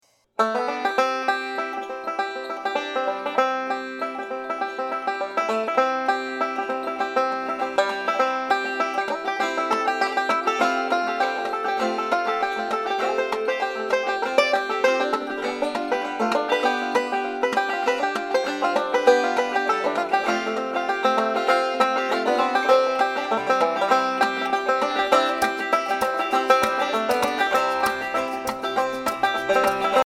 Listen to a sample of the instrumenal track.